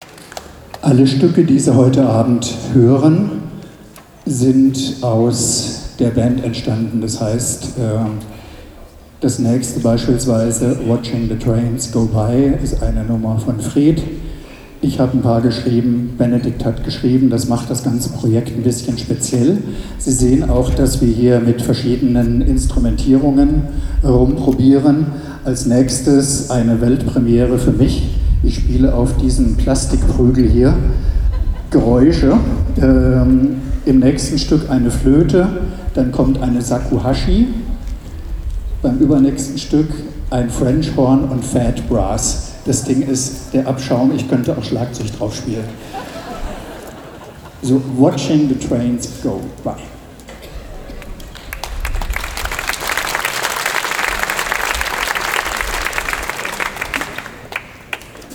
Konzert Regionalabend 31. Jazz im Brunnenhof (Trier)
07 - Ansage.mp3